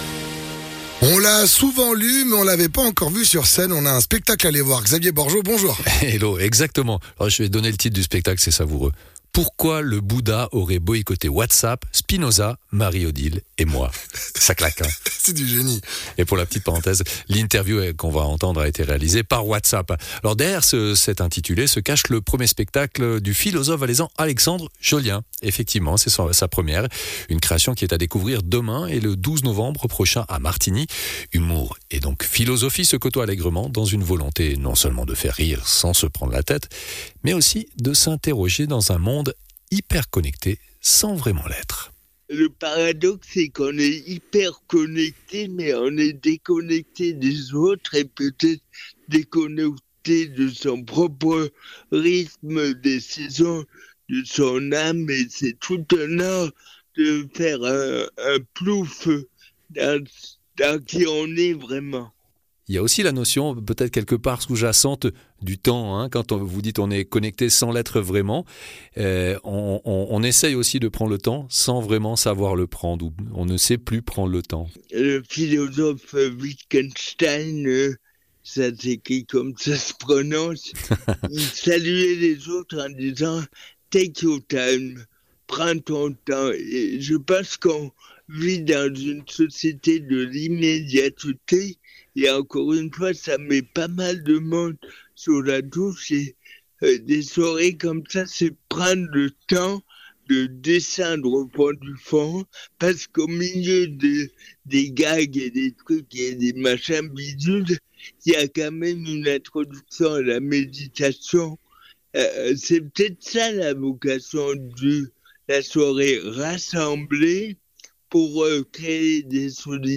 Intervenant(e) : Alexandre Jollien, philosophe